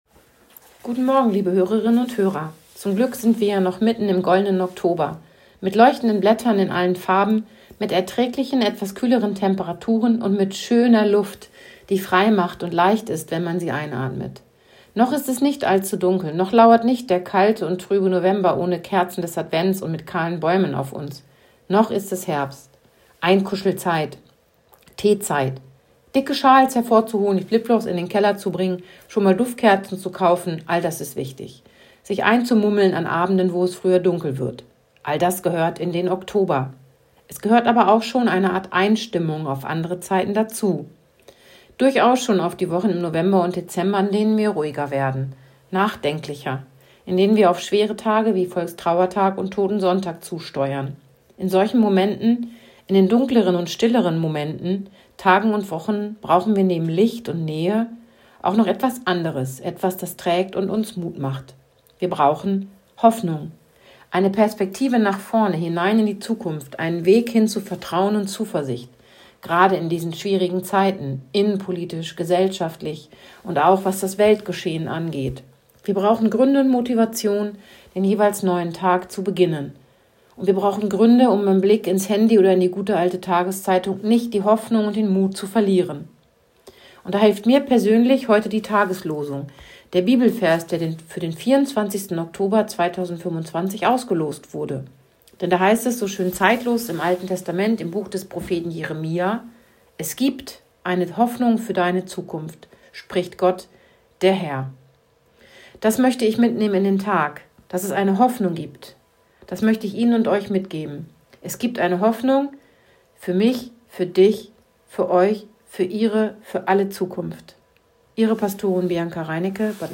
Radioandacht vom 24. Oktober
radioandacht-vom-24-oktober-3.mp3